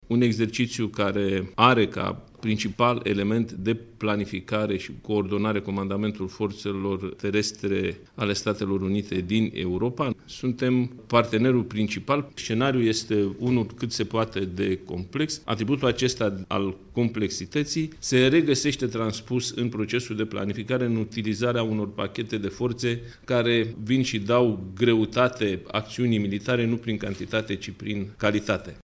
a obţinut o declaraţie despre acest exerciţiu de la şeful Statului Major al Apărării, generalul Nicolae Ciucă: